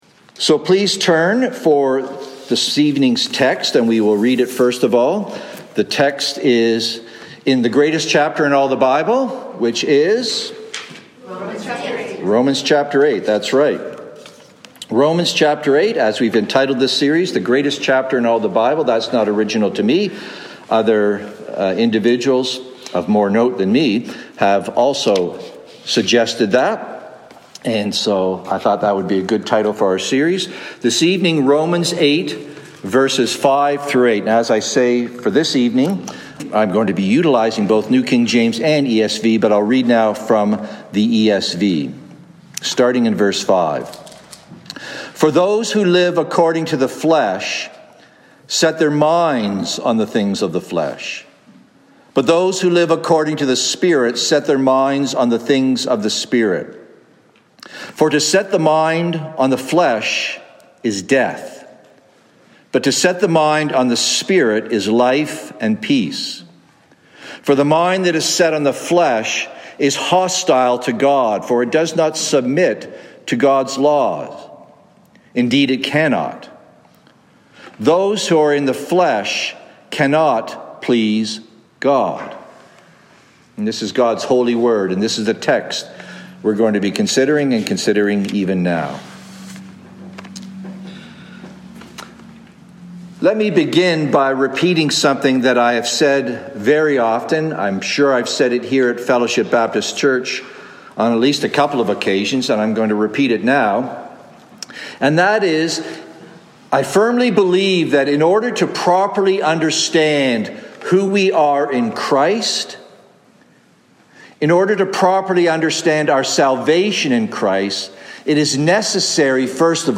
Download Download Reference Rom 8:5-8 Sermon Notes Mar26-23PM.docx From this series "Who Shall Separate?"